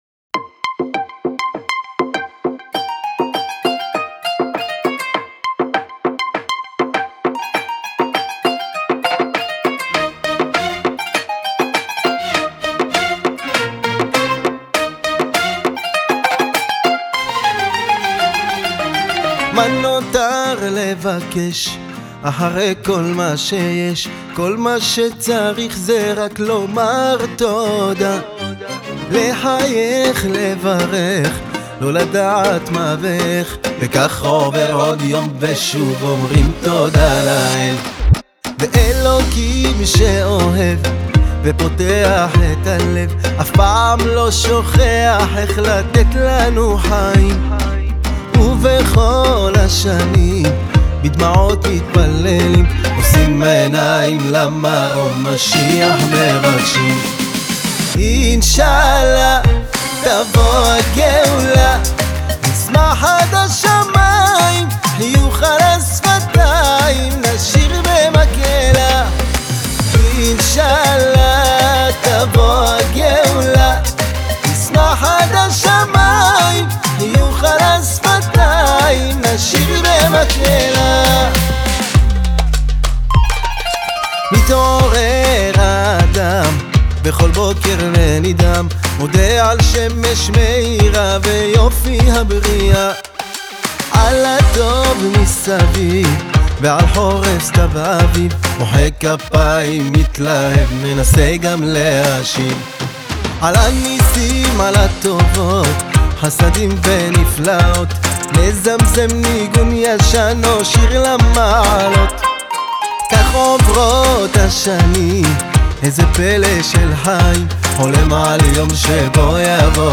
קצבי וסוחף